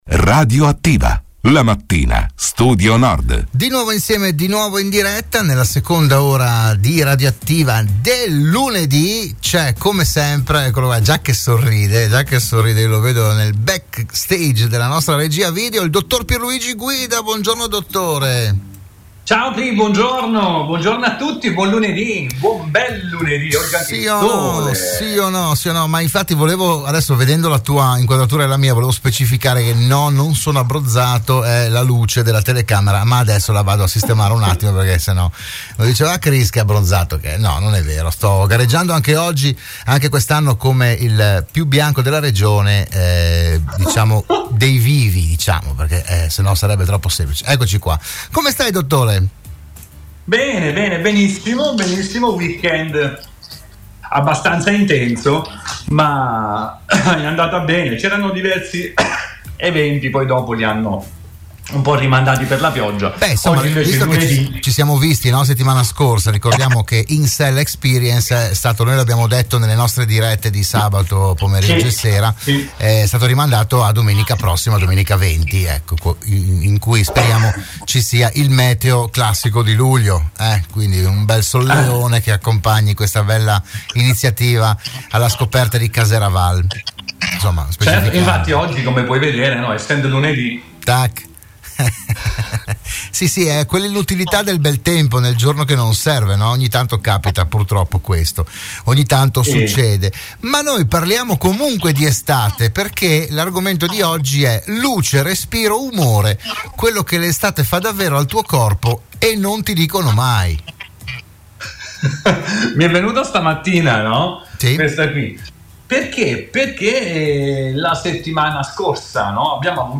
Nuova puntata per “Buongiorno Dottore”, il programma di prevenzione e medicina in onda all’interno della trasmissione di Radio Studio Nord “RadioAttiva”.
parla in modo semplice e chiaro di salute e benessere